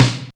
M SNARE 3.wav